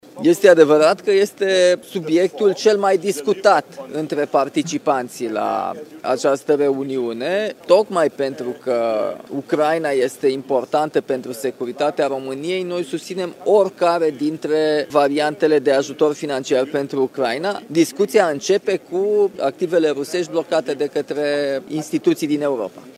Președintele Nicușor Dan: „Discuția începe cu activele rusești blocate de instituții din Europa”